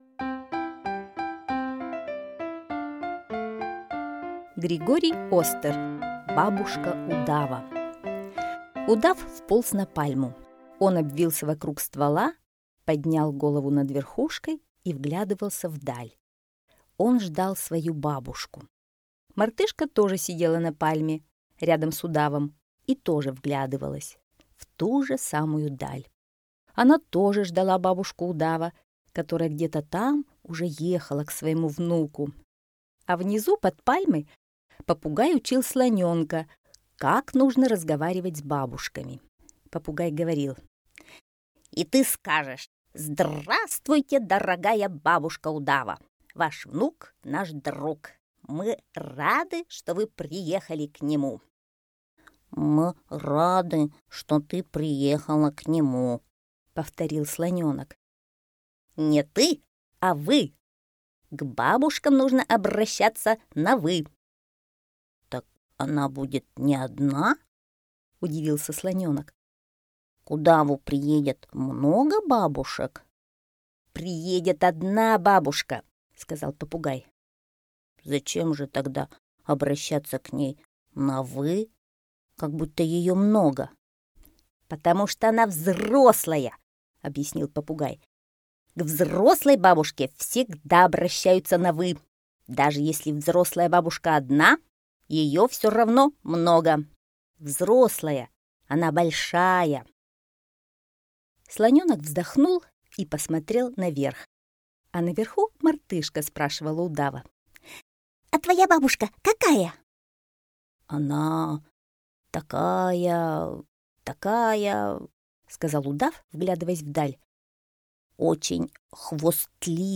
Бабушка удава - аудиосказка Остера Г.Б. Интересная история про то, как к удаву приехала бабушка.